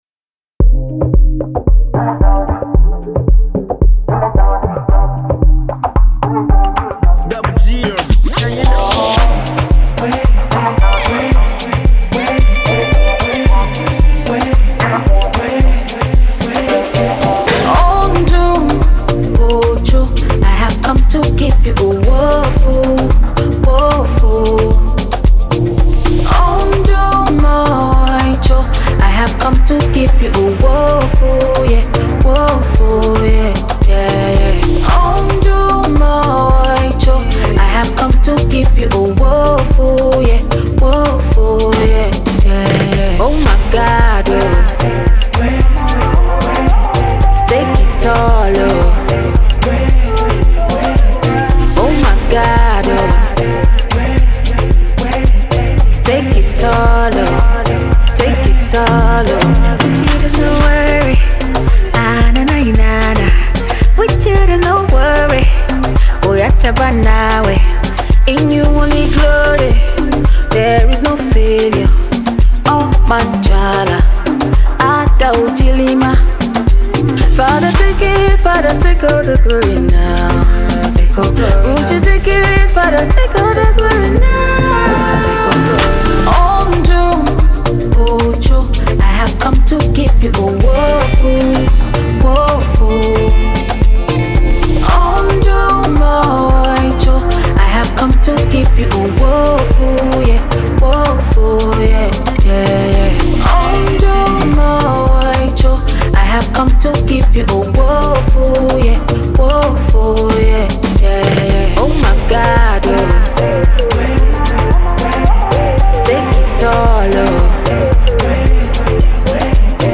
Conscious and Inspirational.